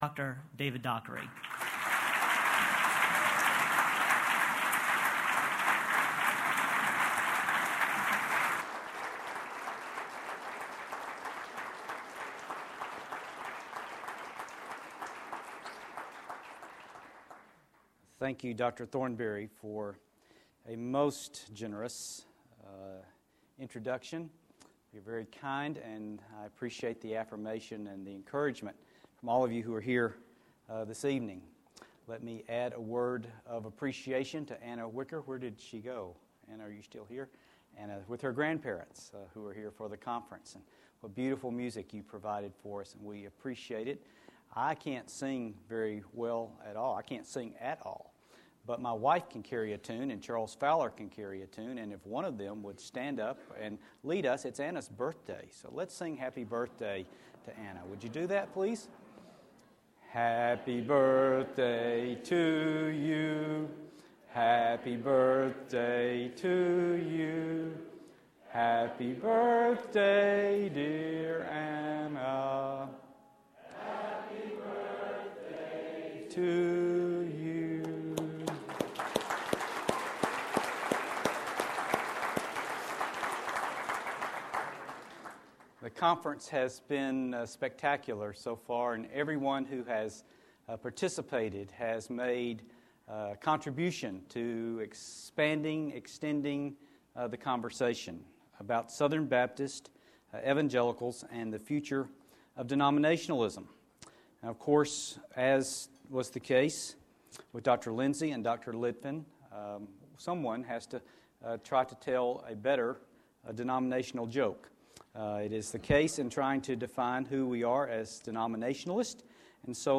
Future of Denominationalism Conference